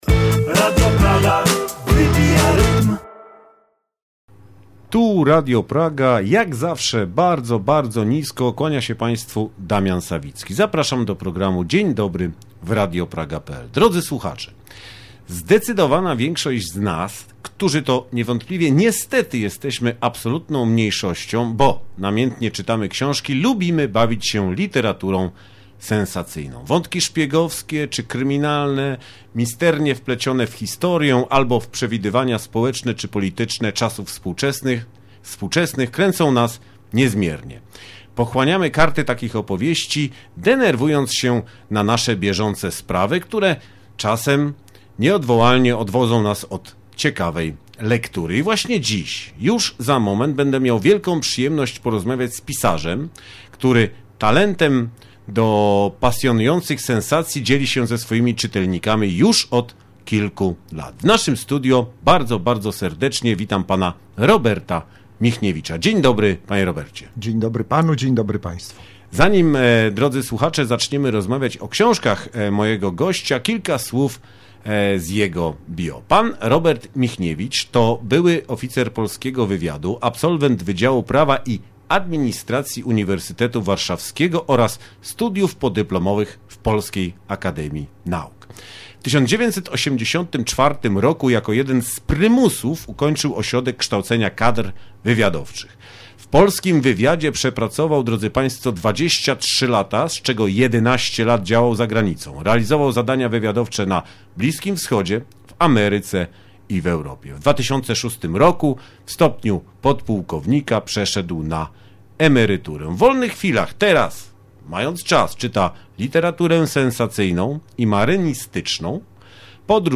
Przed Państwem rozmowa z kimś, kto dla nas takie powieści pisze.